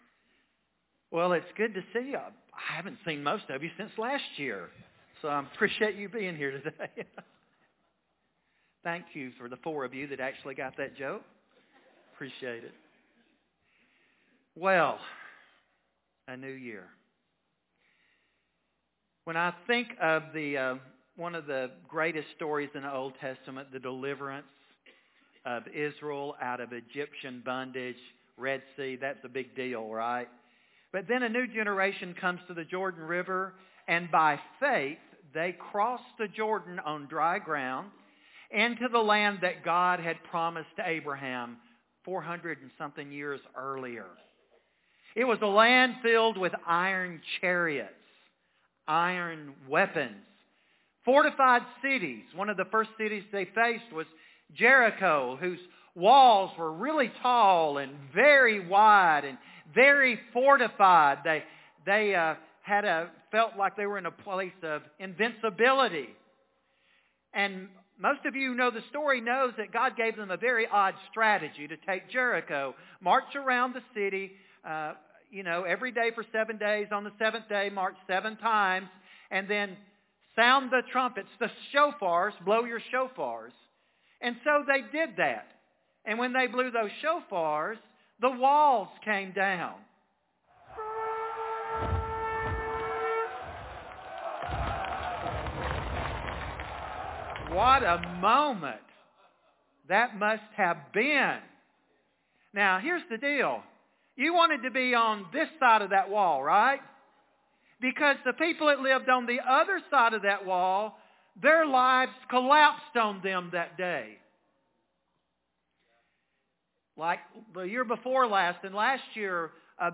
Podcast message